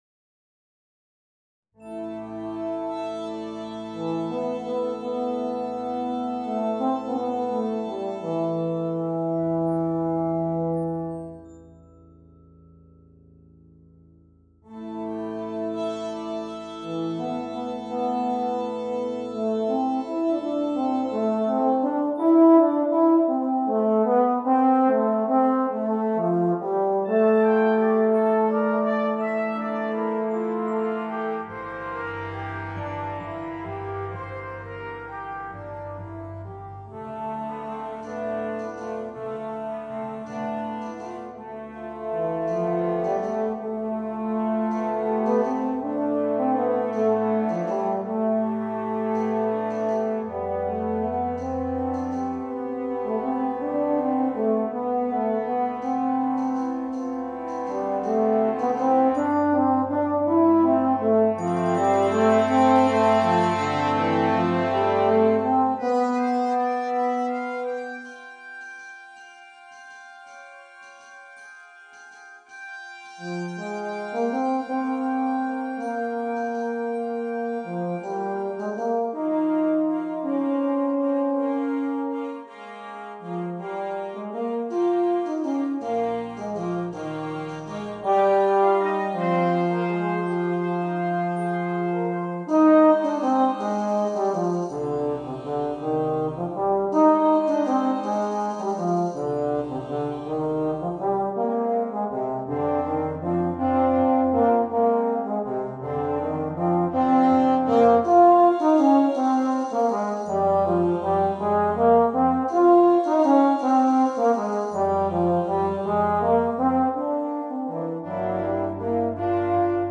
Voicing: Bb Bass and Brass Band